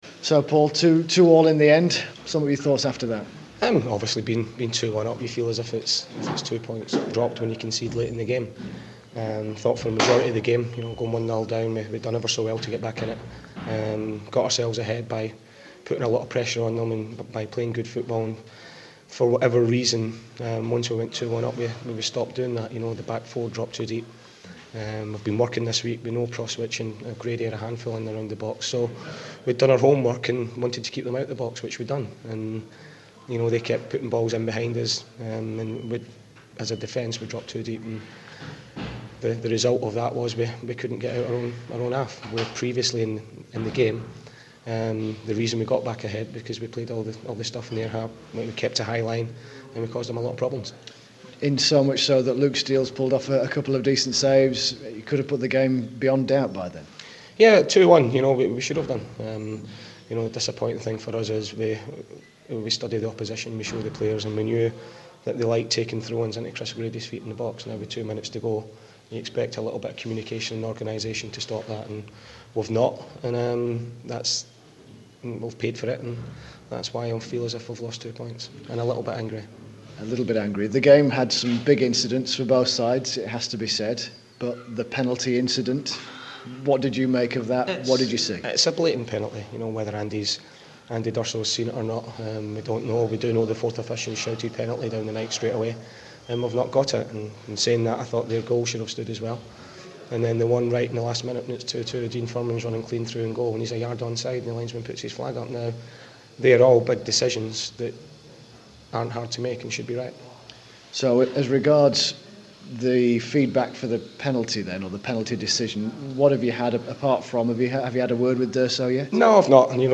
Doncaster manager Paul Dickov on the 2-2 draw with Barnsley